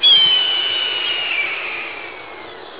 Red-Tailed Hawk Buteo jamaicenses (Accipitridae)
Call
rtailjunglewalk.wav